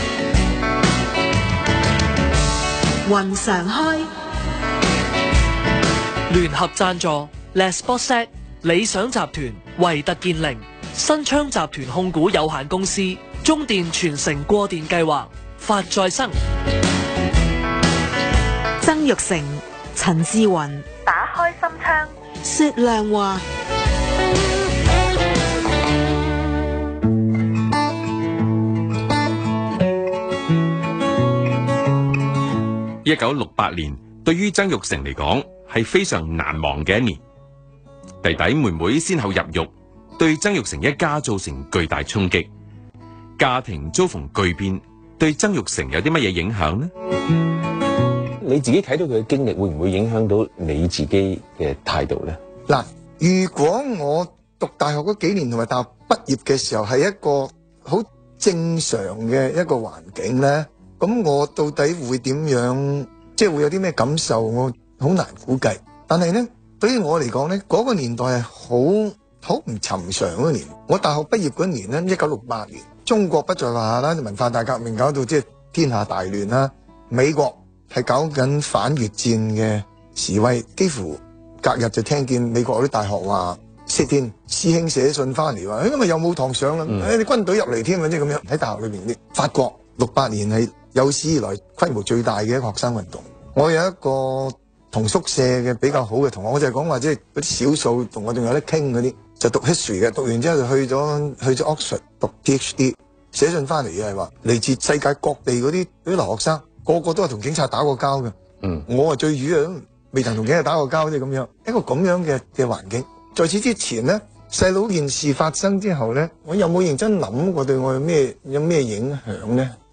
商業電台《雲常開》訪問-第二集
2016年5月10日 商業電台《雲常開》訪問 主持人：陳志雲